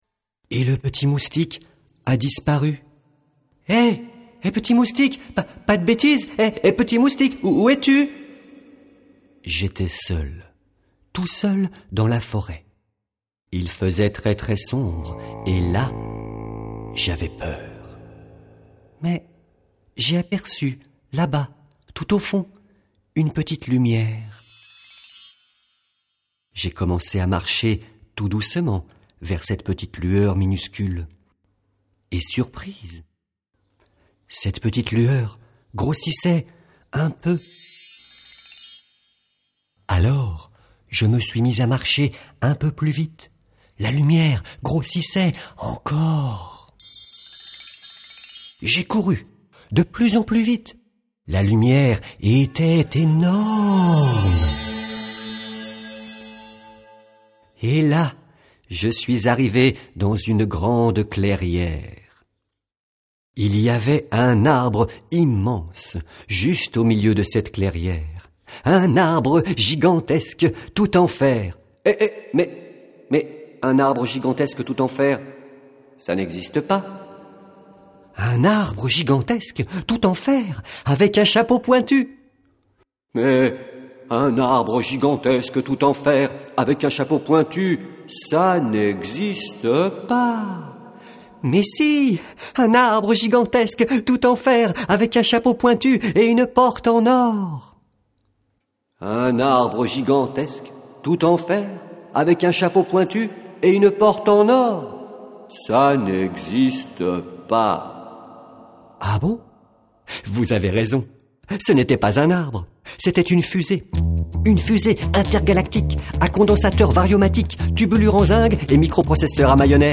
Lecture de Découverte de la Fusée.
Conte pour enfants à télécharger au format mp3.